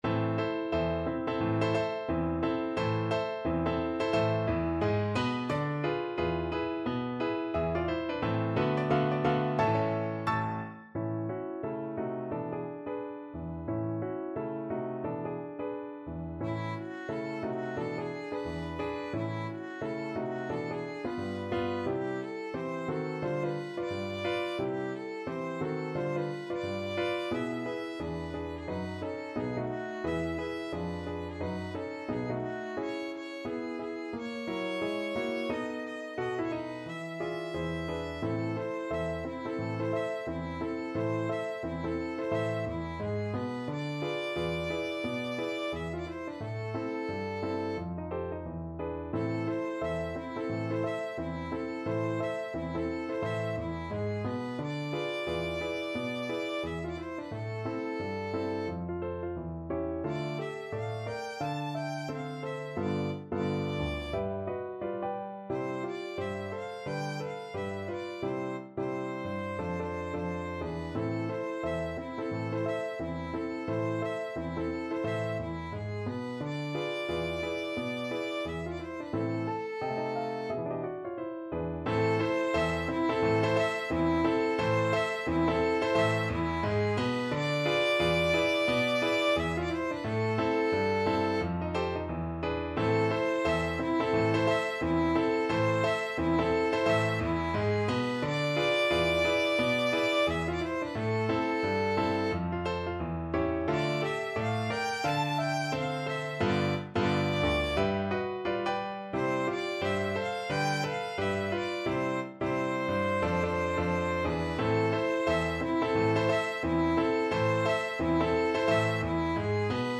~ = 176 Moderato
Jazz (View more Jazz Violin Music)